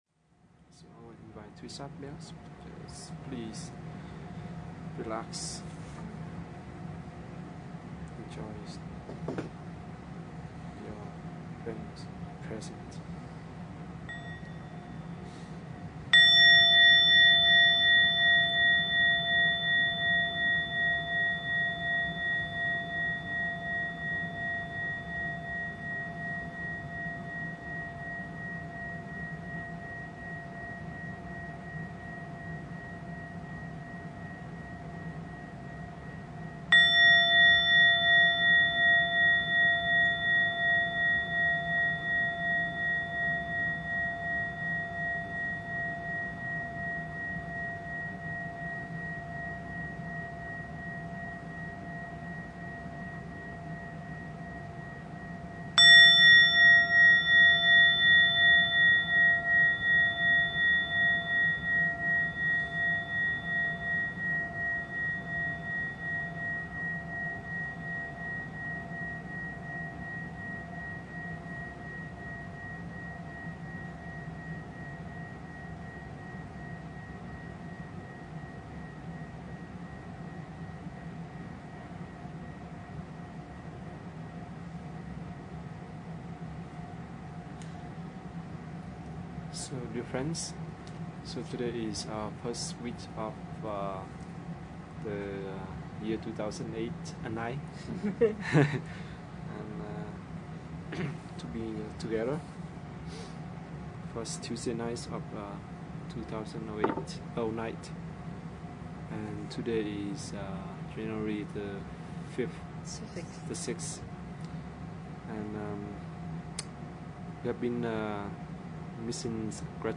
Dharma Talk 1/6/2009